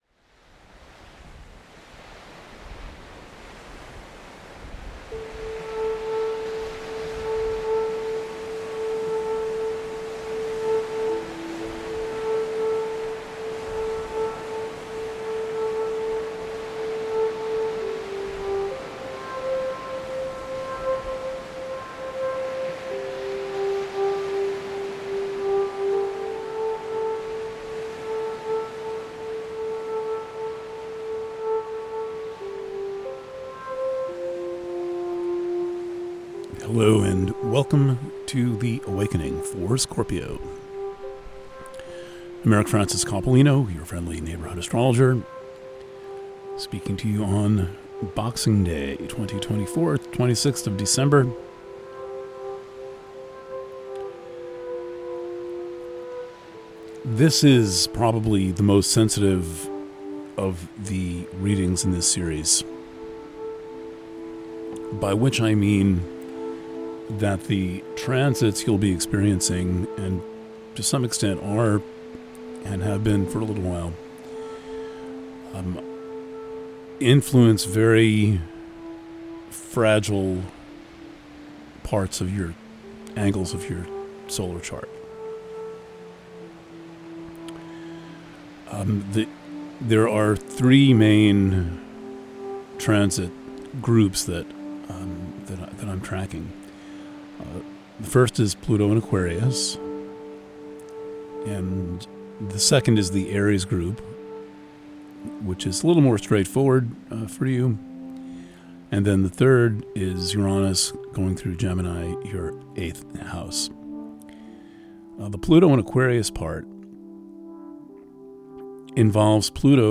Preview – The Awakening for Scorpio Purchasing options for The Awakening Preview – Written reading Preview – The Awakening for Scorpio – PDF Preview – Audio reading Alternate Player (Audio Only) Views: 95